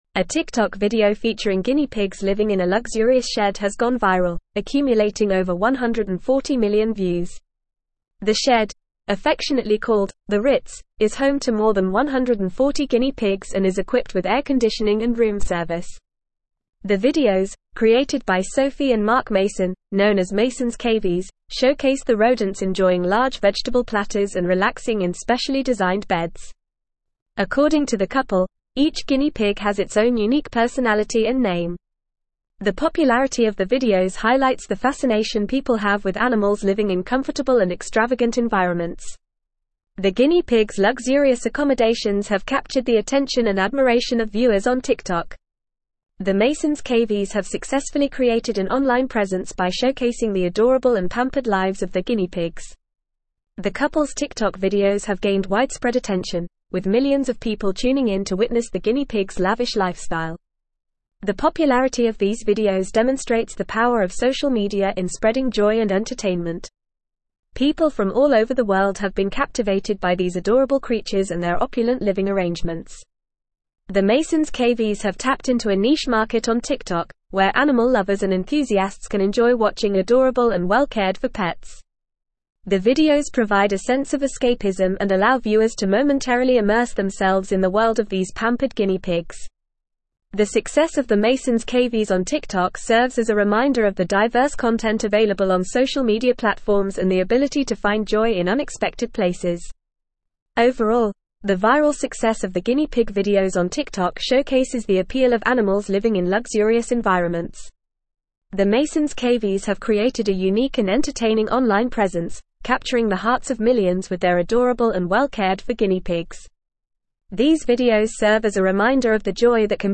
Fast
English-Newsroom-Advanced-FAST-Reading-Guinea-pigs-luxury-shed-goes-viral-on-TikTok.mp3